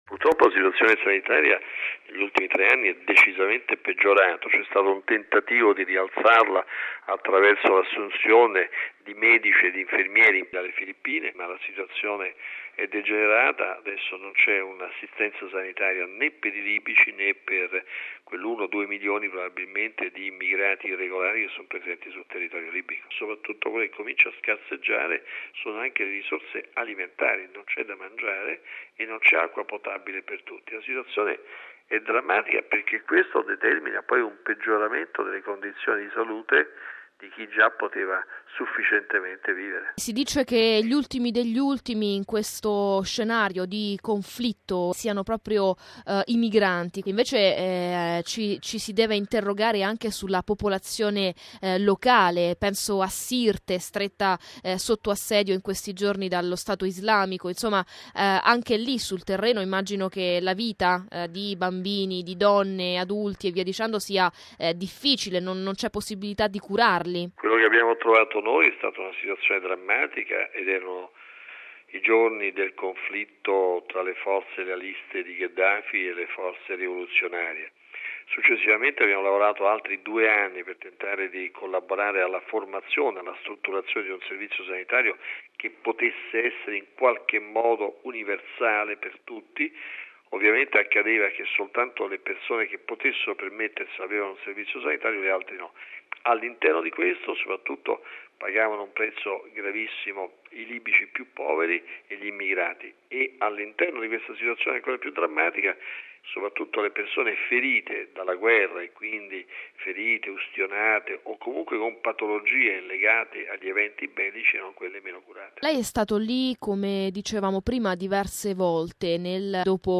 La sua testimonianza al microfono